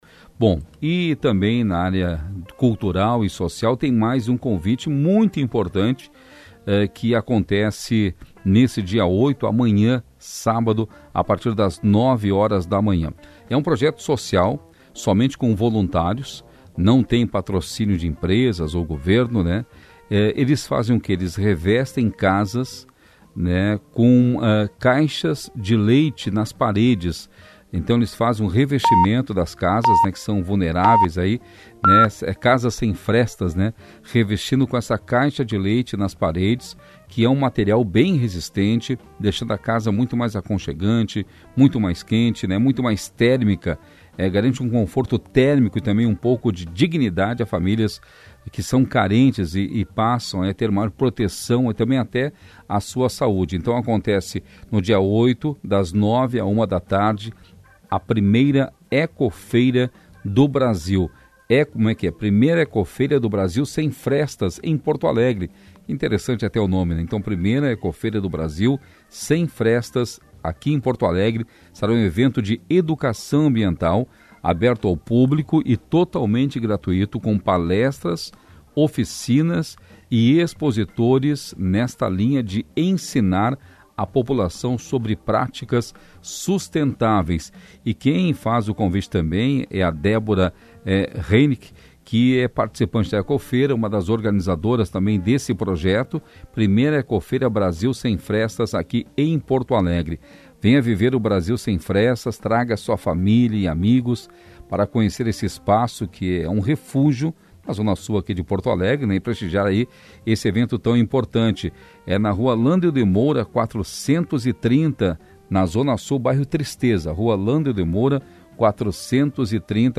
Entrevista para a Rádio Gaúcha sobre a EcoFeira